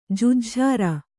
♪ jejhjhāra